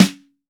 Index of /kb6/Akai_MPC500/1. Kits/Funk Set
MIX01_SN.WAV